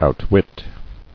[out·wit]